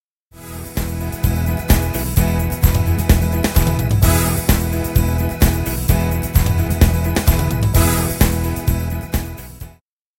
Let’s hear that for the style LiveSoulBand, Variation C (all examples have been recorded using a Tyros 3):
Example 1a (fill-in triggered late: only the rhythm parts of the fill-in will sound)
The rhythm parts will sound, while the instrumental parts just continue to play the content of the main variation C. Now triggering the fill-in at the start of the measure surly makes a huge difference: